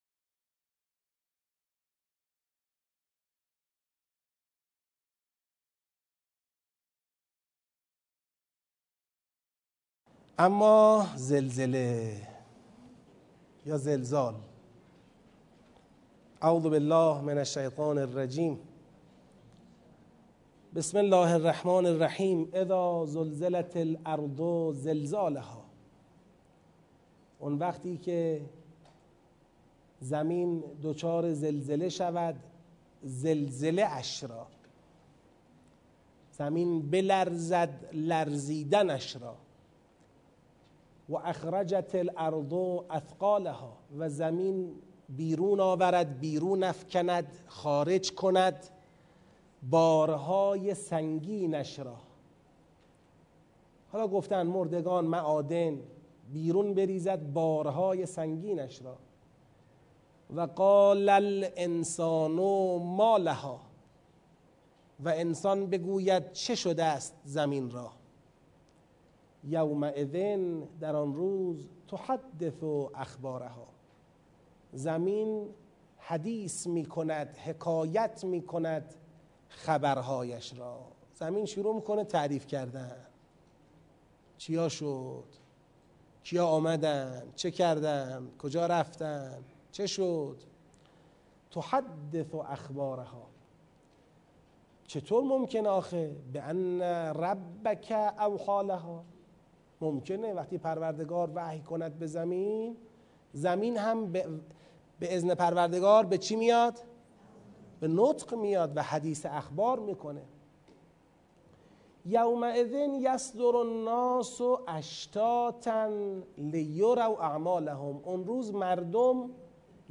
آموزش تدبر در سوره زلزال